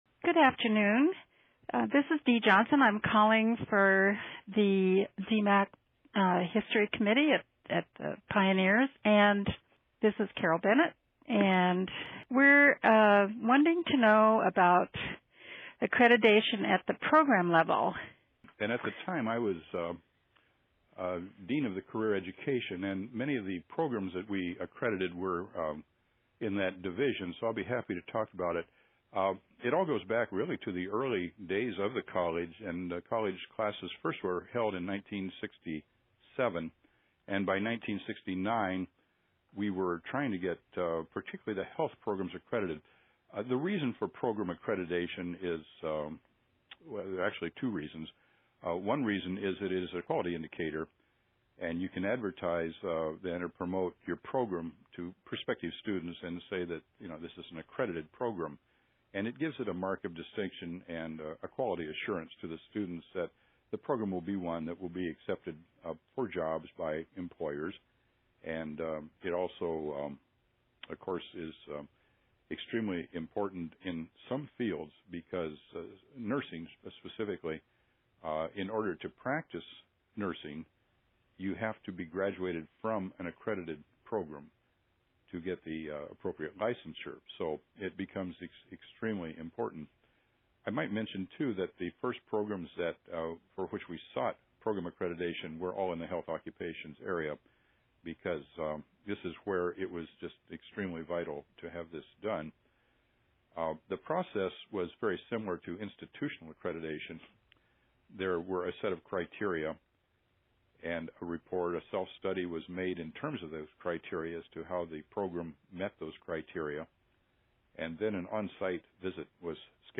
Interviews
Listen to conversations with former DMACC employees by clicking an audio player below.